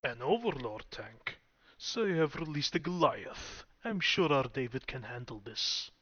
Subject description: My personal VO set   Reply with quote  Mark this post and the followings unread